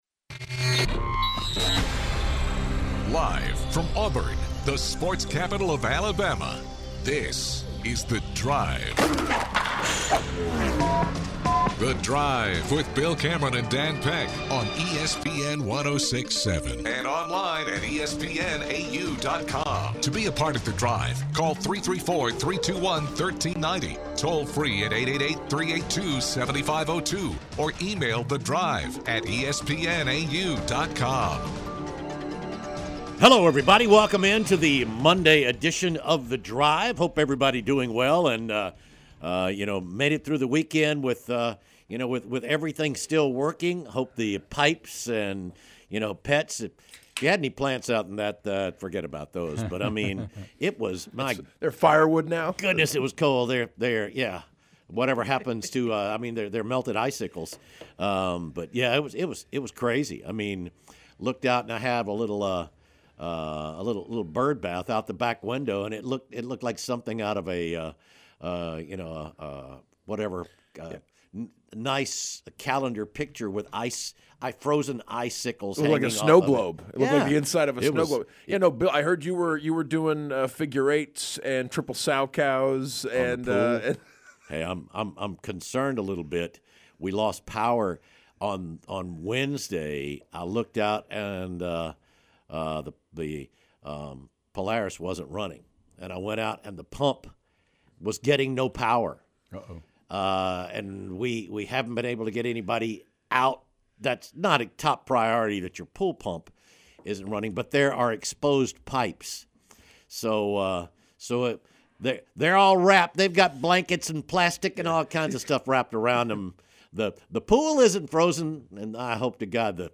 Callers wonder why Auburn's loss to UT played out so differently than recent games.